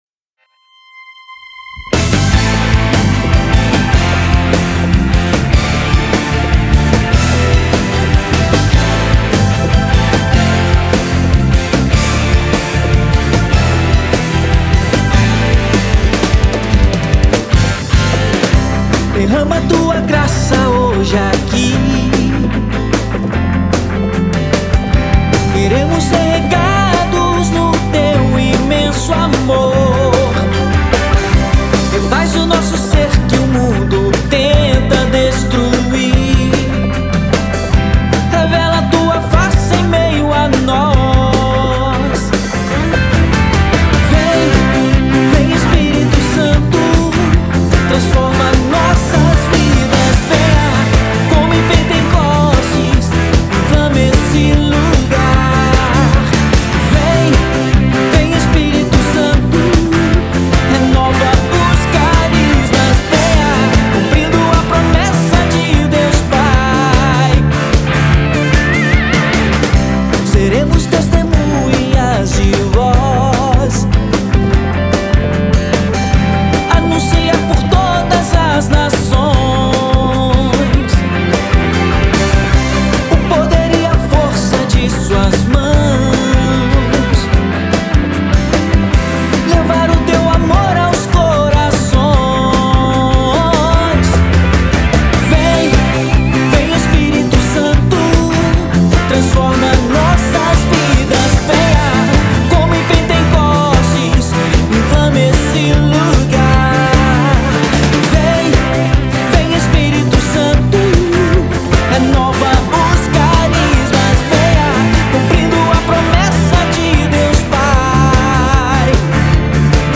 Música Católica